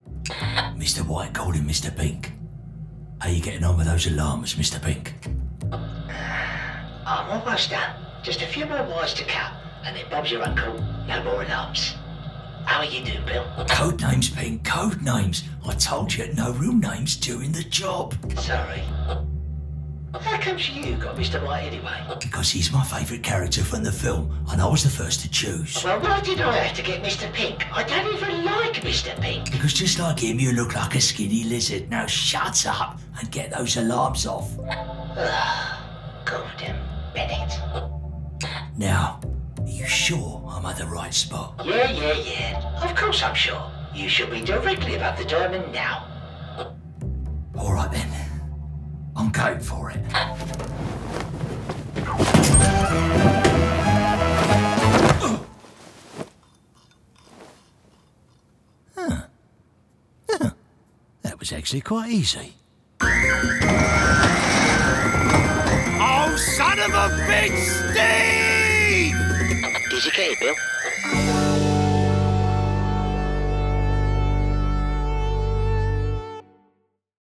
Character Voice Samplers
Character Voices
Character-Voices.mp3